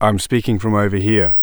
Testing Signal
dryspeech.wav